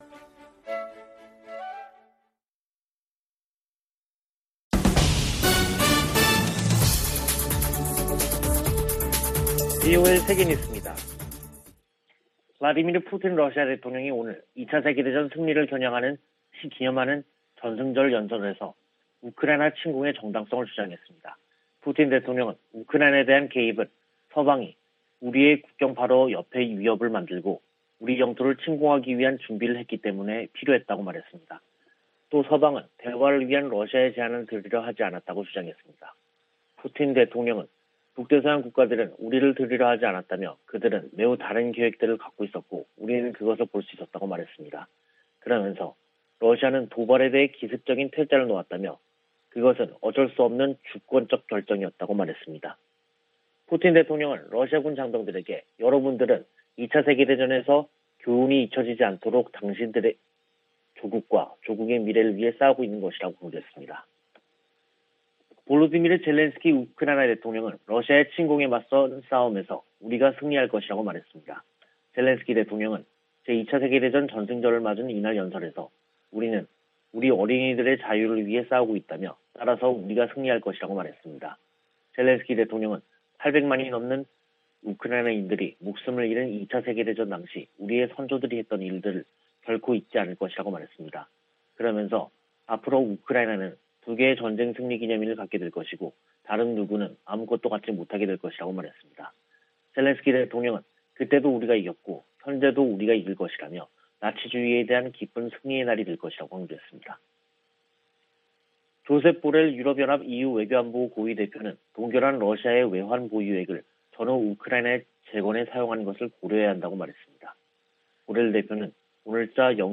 VOA 한국어 간판 뉴스 프로그램 '뉴스 투데이', 2022년 5월 9일 2부 방송입니다. 북한이 7일 오후 함경남도 신포 해상에서 잠수함발사 탄도미사일(SLBM)을 발사했습니다. 미 국무부는 미사일 도발을 이어가는 북한을 규탄하고 한・일 양국에 대한 방어 공약을 재확인했습니다. 미 하원에서 오는 12일 올해 첫 대북 정책 청문회가 개최될 예정입니다.